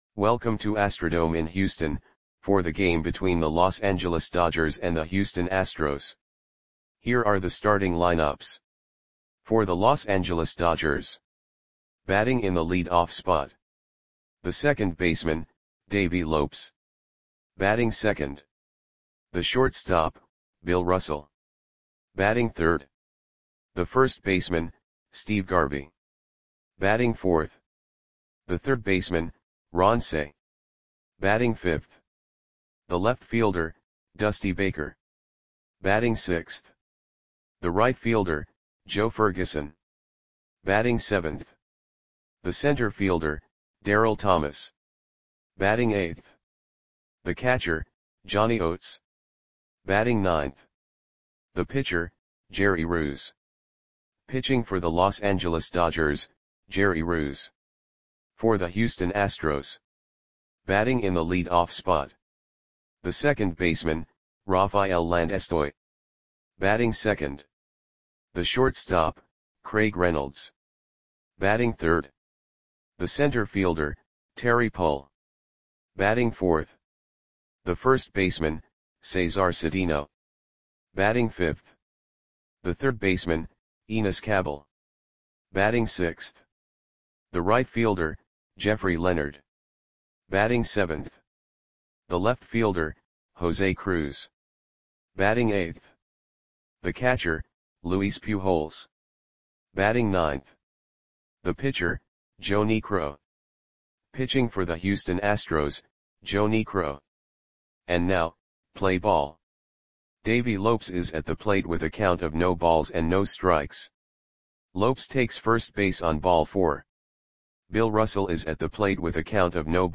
Audio Play-by-Play for Houston Astros on September 3, 1979
Click the button below to listen to the audio play-by-play.